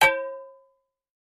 fo_fryingpan_clang_01_hpx
Frying pans clang together. Clang, Frying Pan